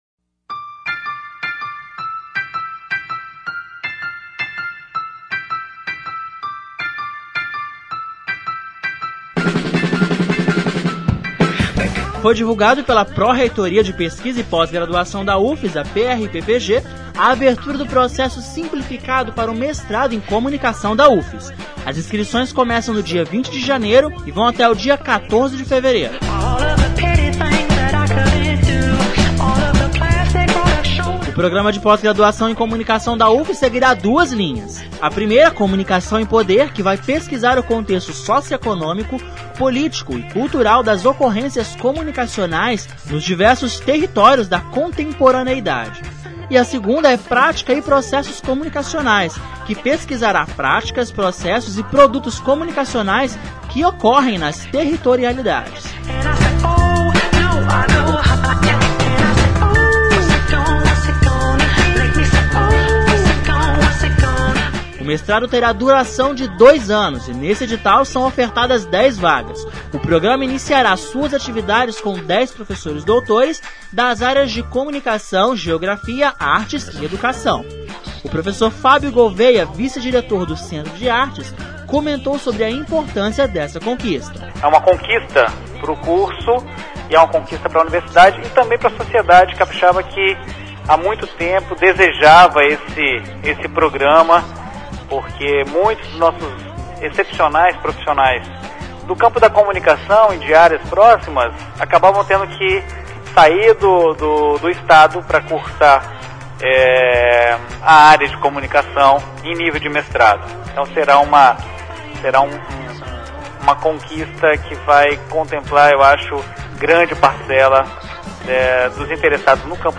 Confira mais informações na reportagem.